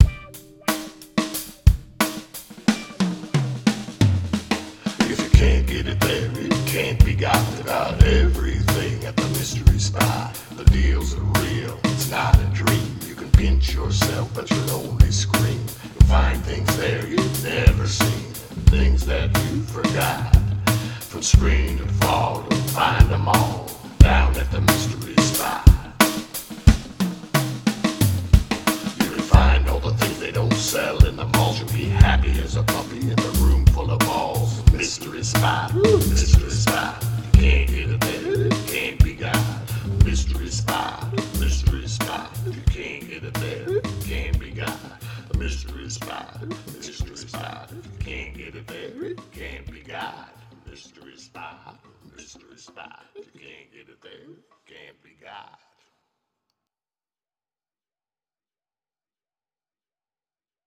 We began a fun correspondence and he sent me all sorts of surprise packages of his amazing paper cuttings, music and, one day…..a Mystery Spot theme song which he wrote, played, sang and recorded!
Mystery Spot Theme Song